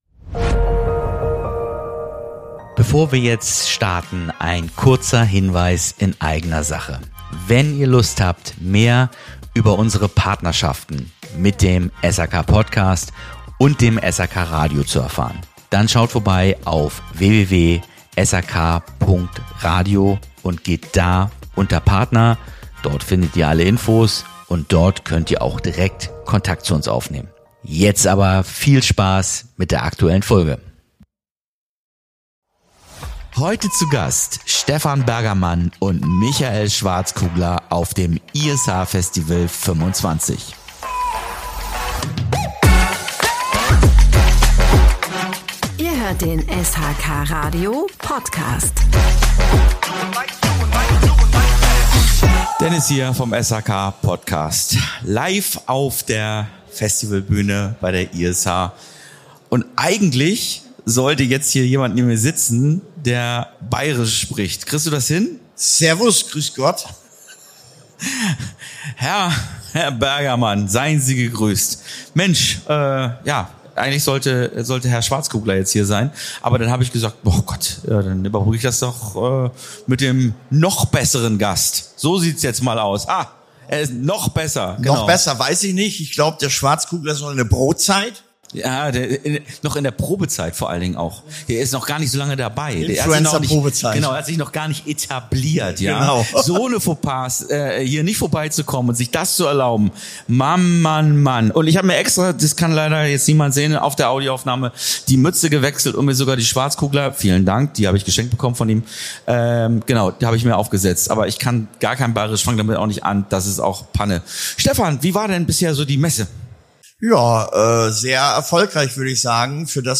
Beschreibung vor 10 Monaten In Folge #75 des SHK PODCASTS wird’s lustig, spontan und ein bisschen dynamisch – eben genau so, wie wir’s auf der Bühne beim ISH Festival 2025 erlebt haben!